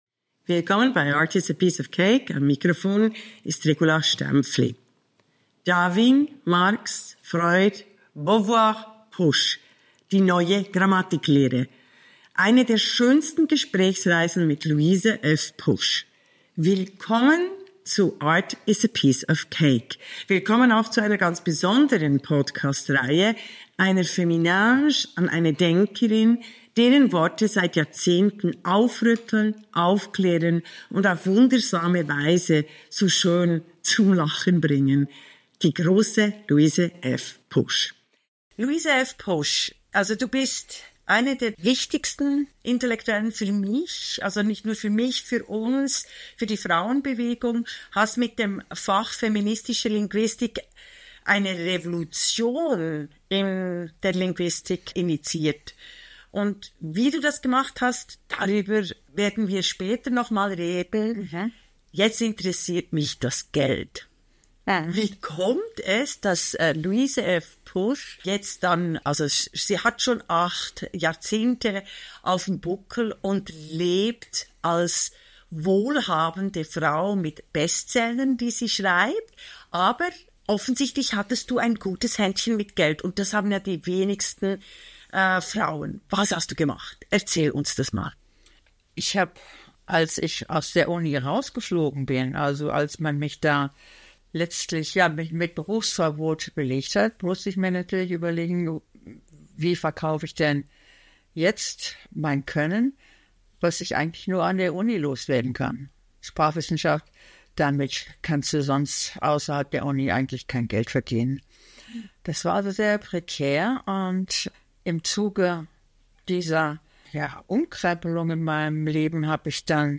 Eine Gesprächsreihe durch feministische Grammatik & Alltag. vor 10 Monaten Luise F. Pusch